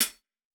Closed Hats
TC2 Live Hihat9.wav